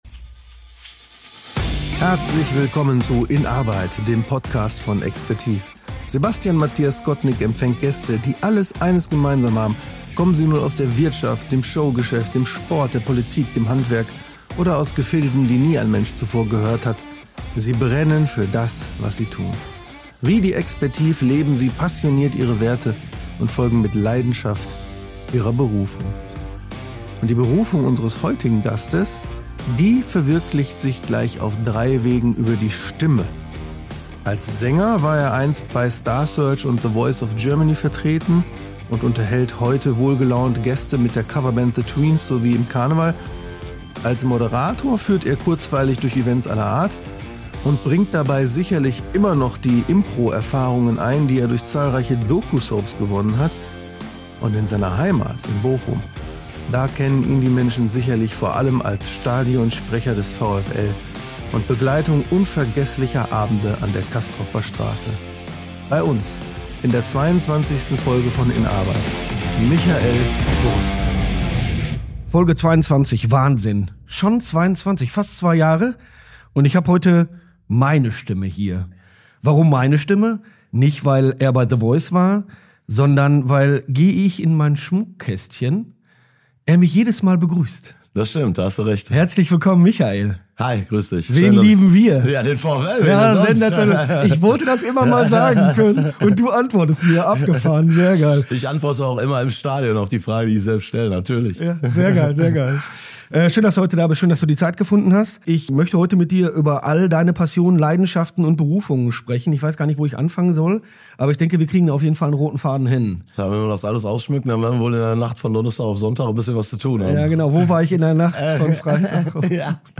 Ein Gespräch über die Heimat und über die Kunst, das Feuer des Hobbys mit der Routine des Profis bestmöglich zu verbinden.